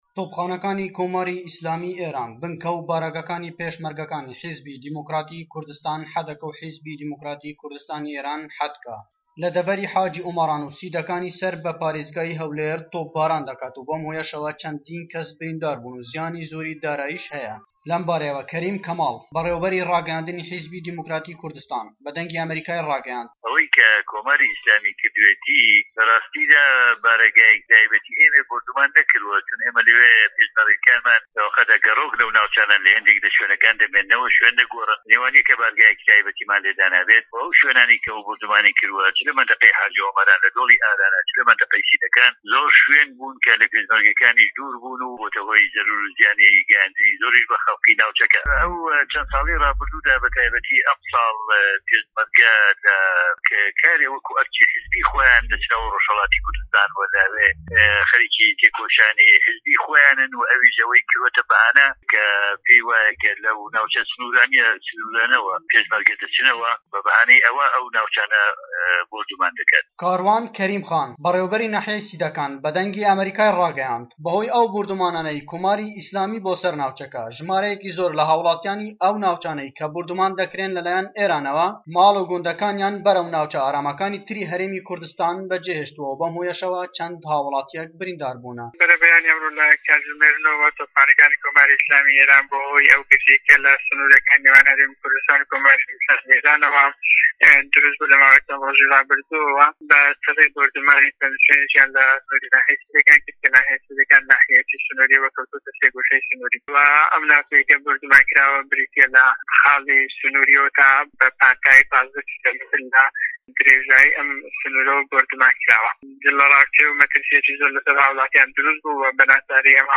دەقی ڕاپۆرتی پەیامنێرمان لە هەولێر